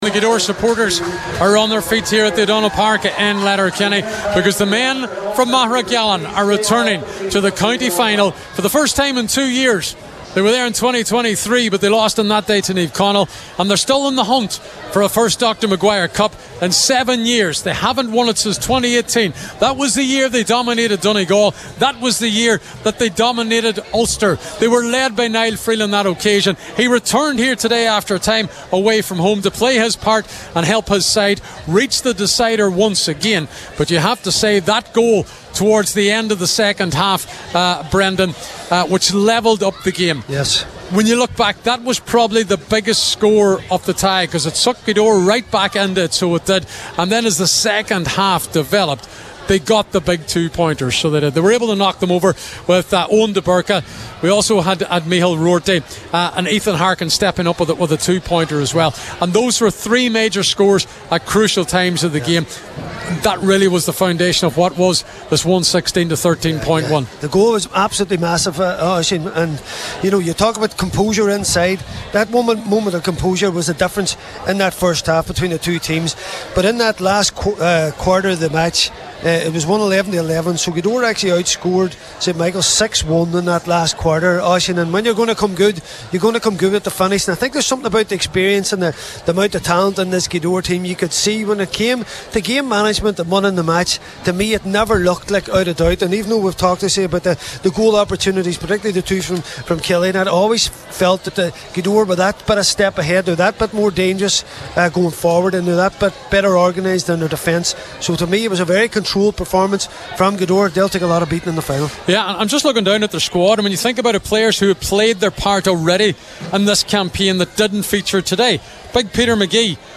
were live in Letterkenny at full time today…